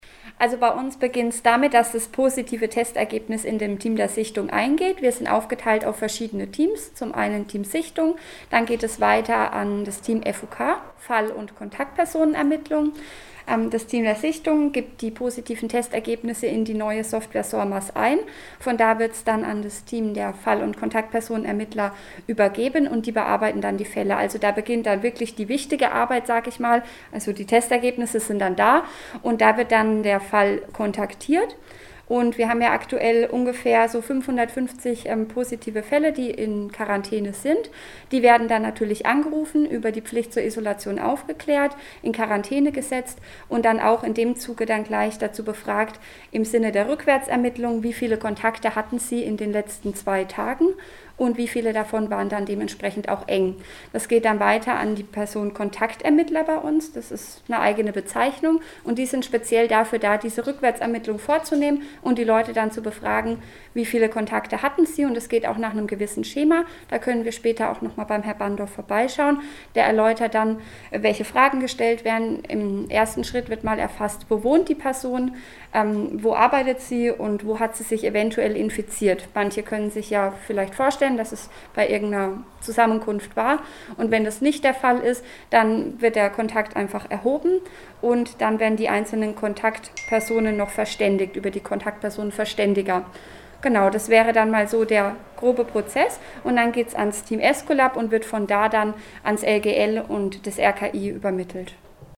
Besuch im Gesundheitsamt Schweinfurt- Alle Interviews und Videos zum Nachhören - PRIMATON